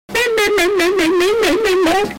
Clash Royale Goblin Crying Boosted Sound Button - Free Download & Play